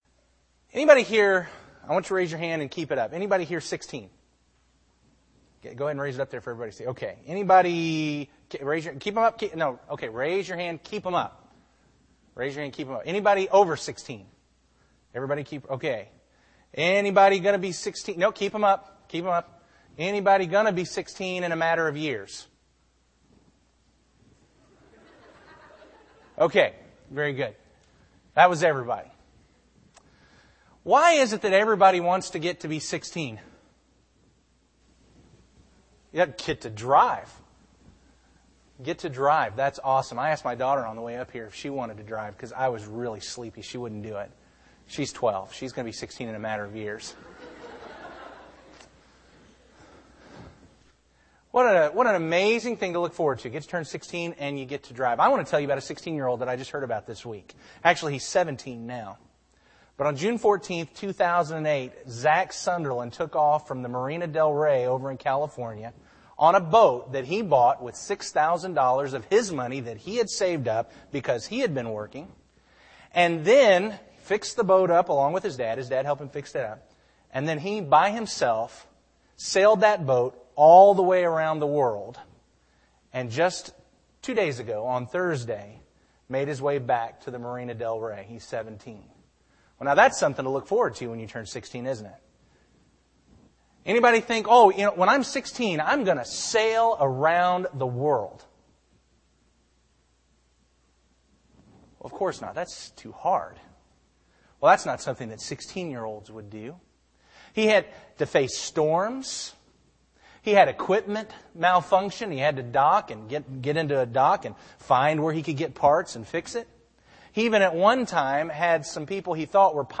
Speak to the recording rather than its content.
Service: Special Event Type: Sermon